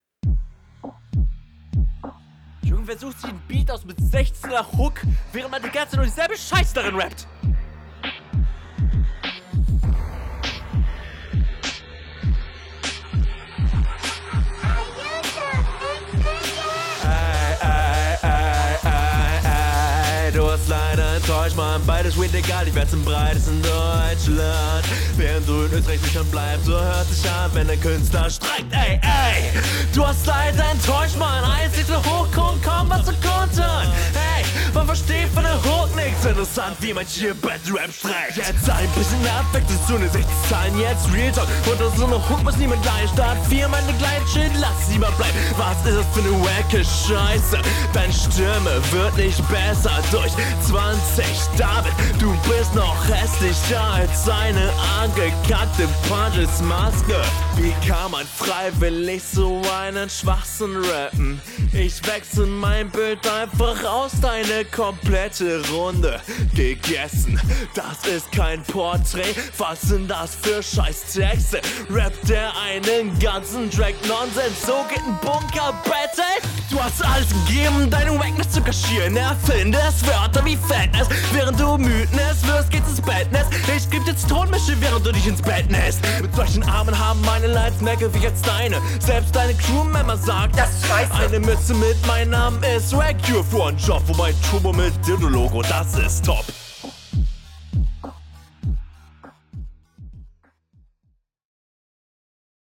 zu leise aber coolerer stimmeinsatz